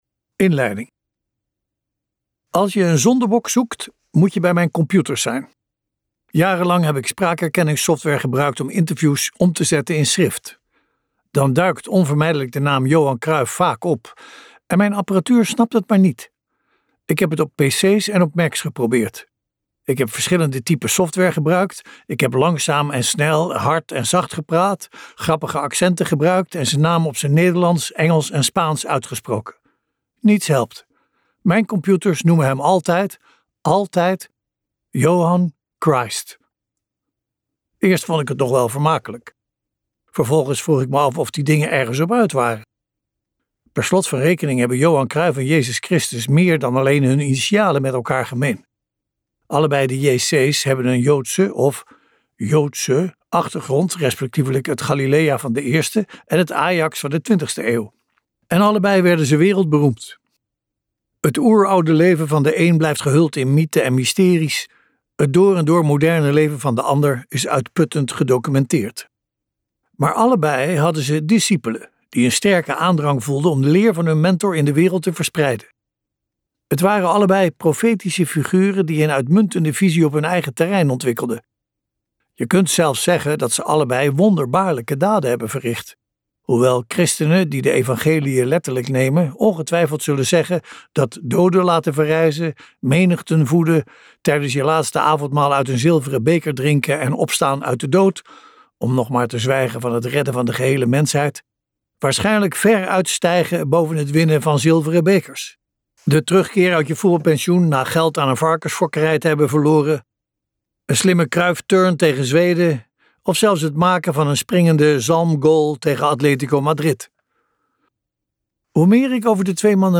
Ambo|Anthos uitgevers - Waarschijnlijk onsterfelijk luisterboek